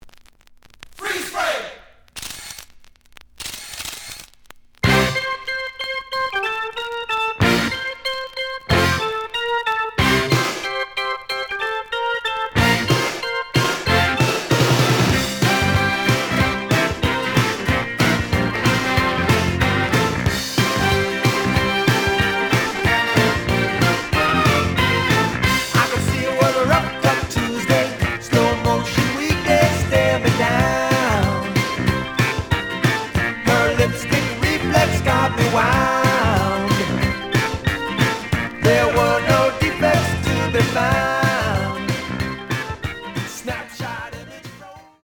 The audio sample is recorded from the actual item.
●Genre: Rock / Pop